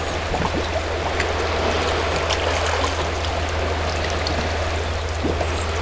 Boatharbor
BoatHarbor.wav